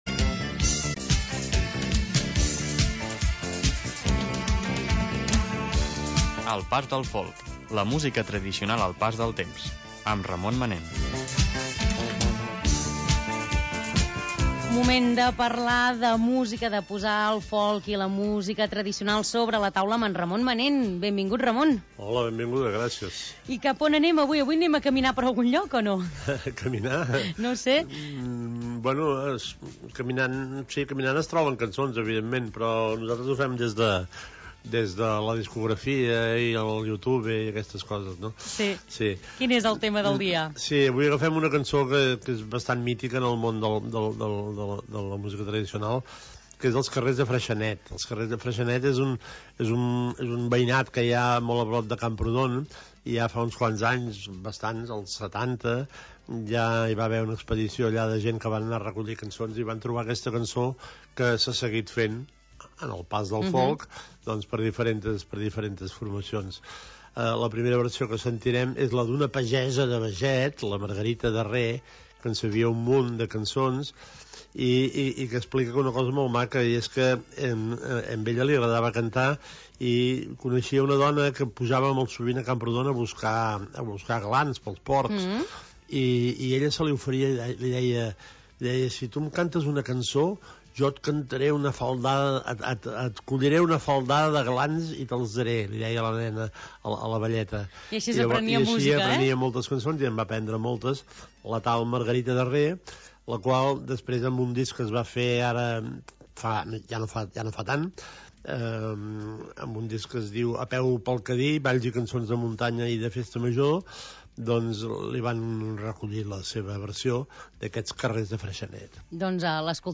espai de música tradicional